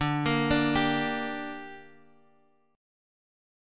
Dsus4#5 chord